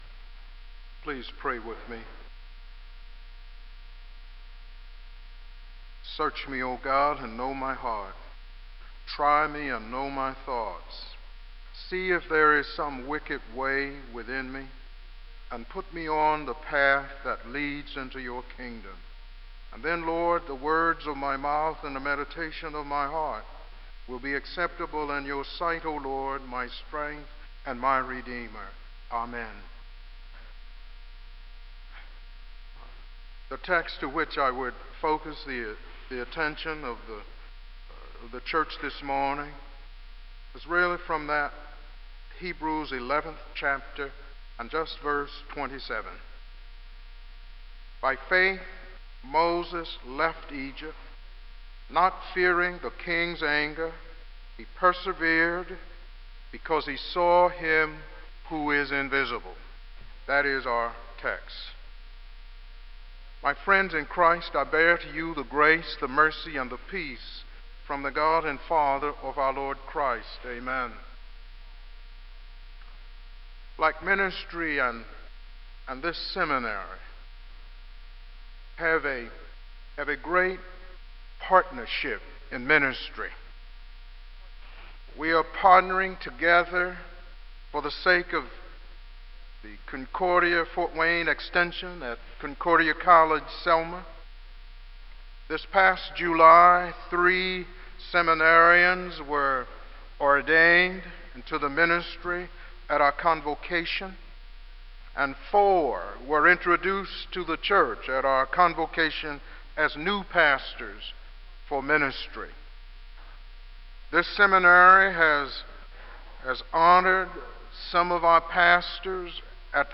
Kramer Chapel Sermon - February 02, 2000